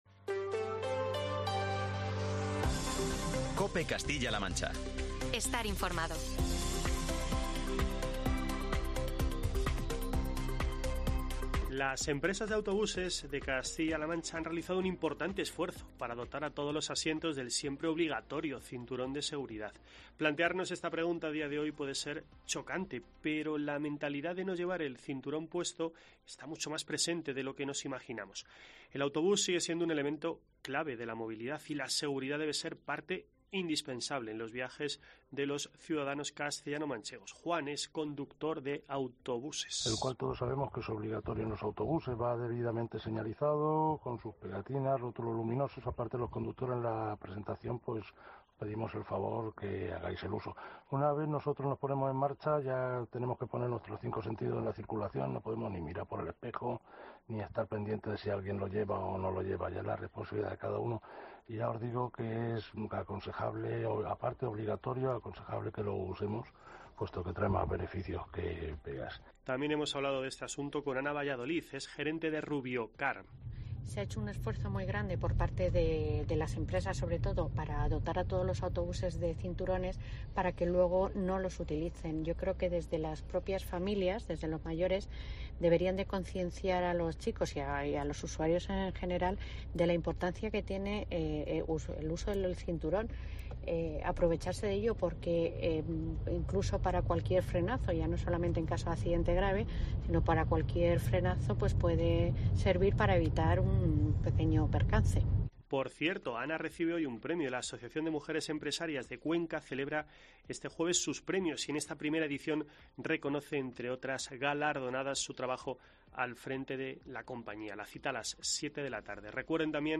Un conductor de autobús alza la voz sobre los cinturones de seguridad de los pasajeros